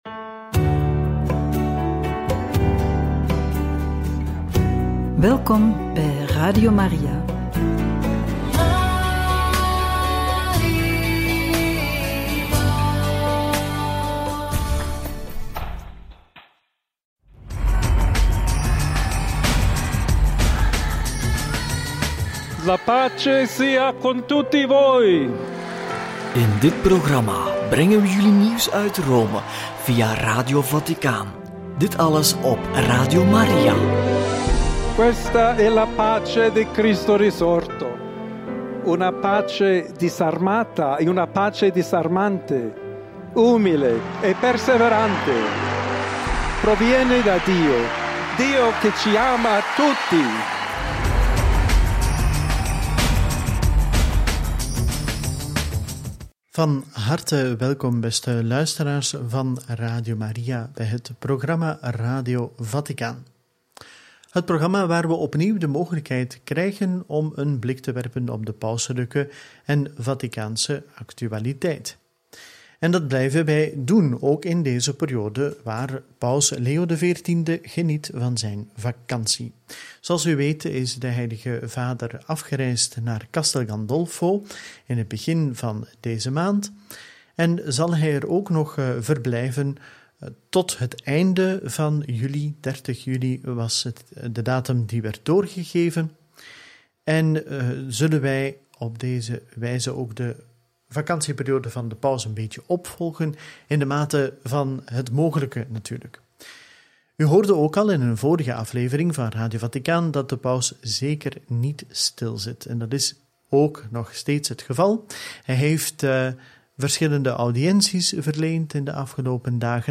Eucharistie in Castel Gandolfo – Angelus 13/7 – Boodschap ‘Wedstrijd van het hart’ – Radio Maria